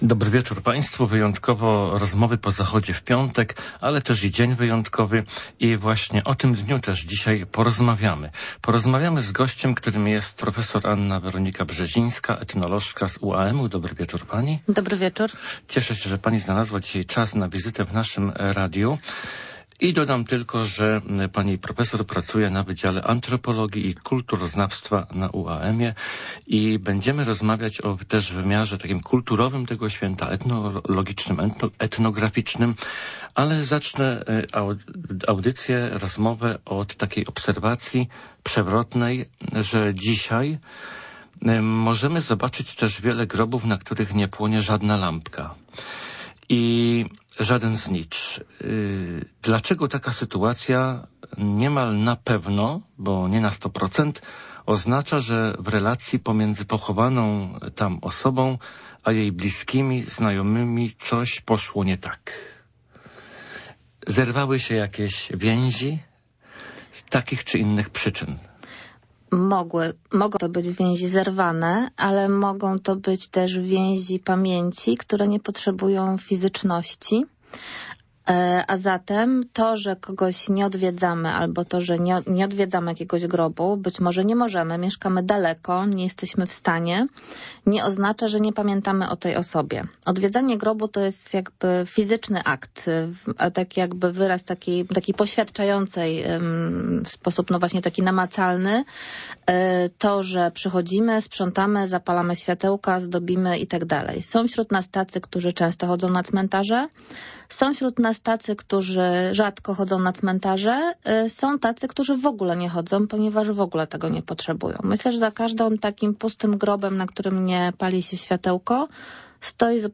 rozmawiamy z etnolożką